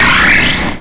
pokeemerald / sound / direct_sound_samples / cries / klang.aif